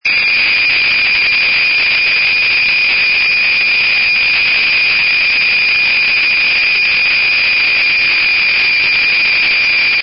With the capacitor “C” selected at .1 to .25 MF, the circuit will produce an audio tone.
TA165_PCT_Oscillator_Audio.mp3